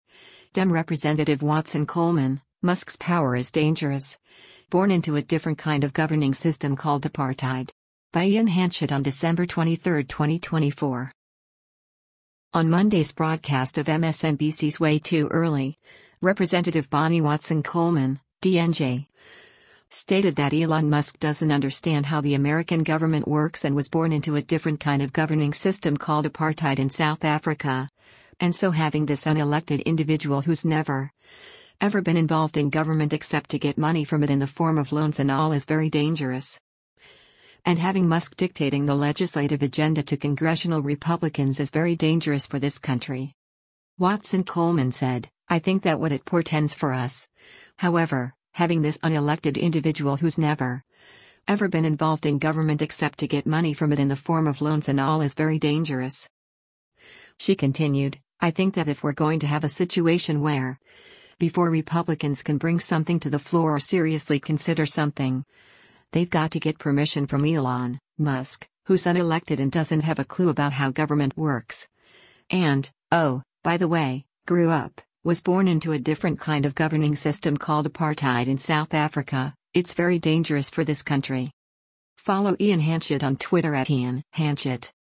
On Monday’s broadcast of MSNBC’s “Way Too Early,” Rep. Bonnie Watson Coleman (D-NJ) stated that Elon Musk doesn’t understand how the American government works and “was born into a different kind of governing system called apartheid in South Africa,” and so “having this unelected individual who’s never, ever been involved in government except to get money from it in the form of loans and all is very dangerous.”